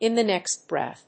アクセントin the néxt bréath